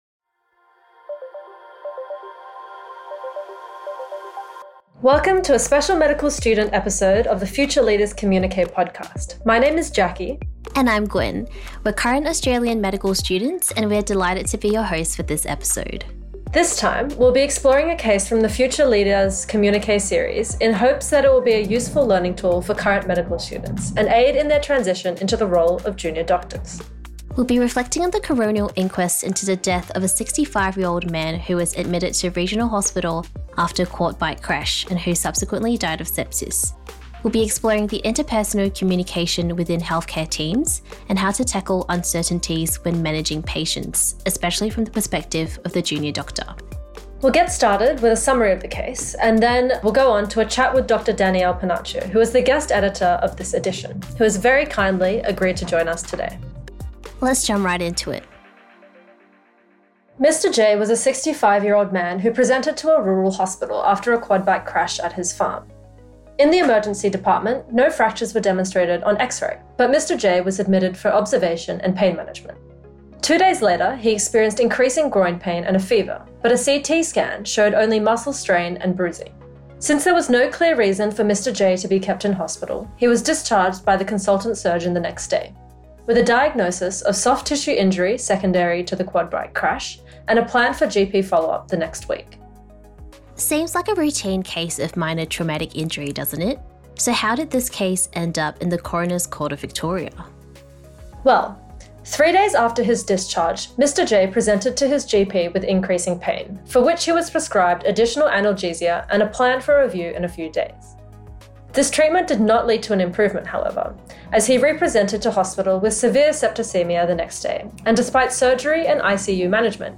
This podcast is hosted by two medical students